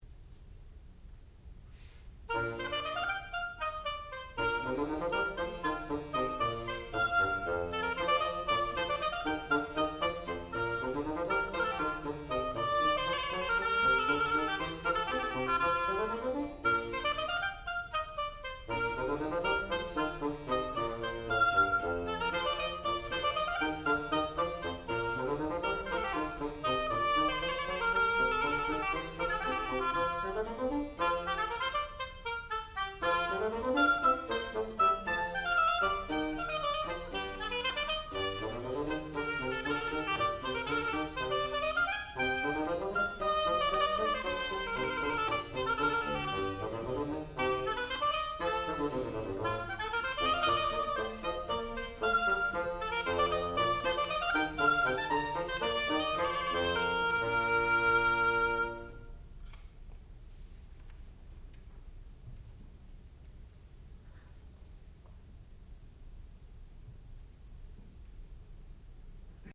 Partita No.1 in B Flat for oboe and continuo
classical music
harpsichord